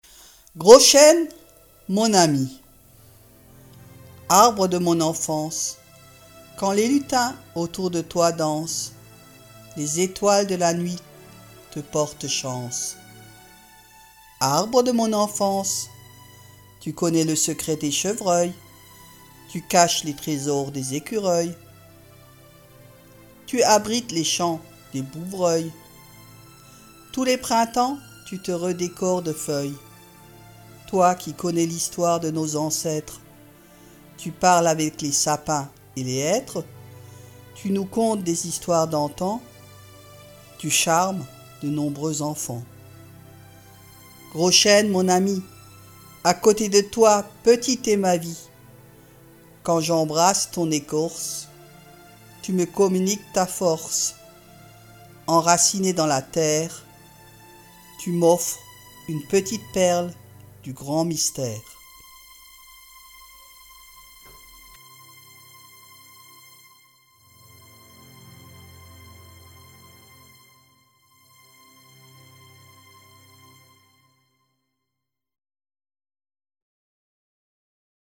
enregistrement du poème accompagné